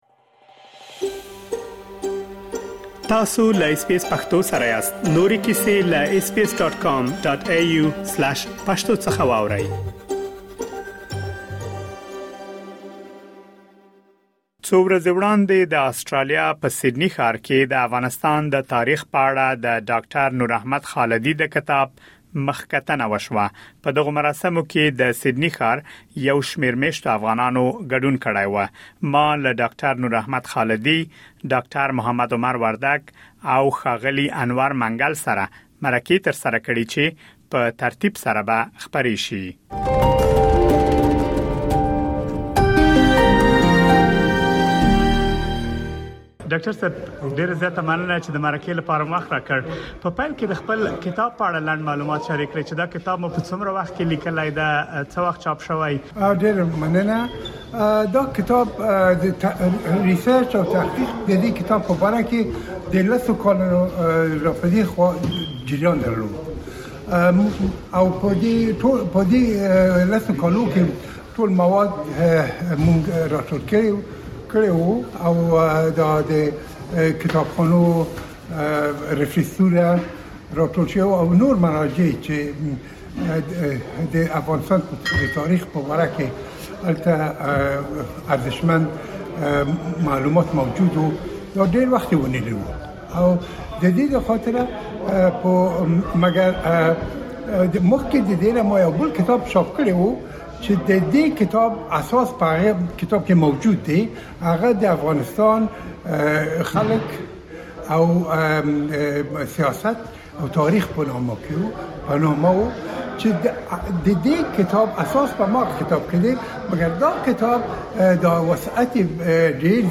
مرکې ترسره کړي دي